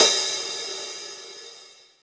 paiste hi hat5 open.wav